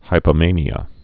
(hīpə-mānē-ə, -mānyə)